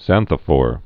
(zănthə-fôr)